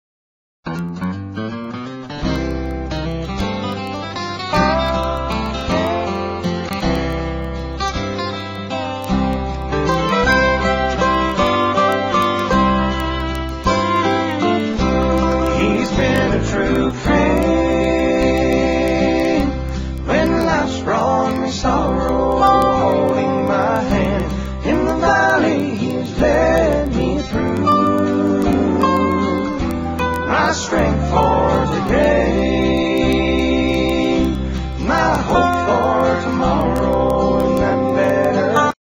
4 Tracks With BACKGROUND VOCALS